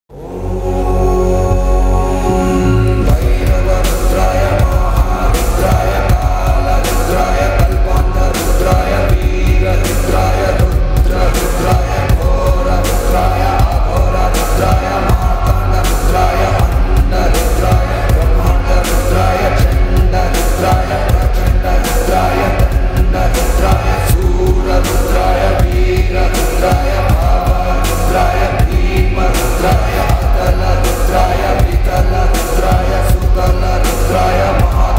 bhajan ringtone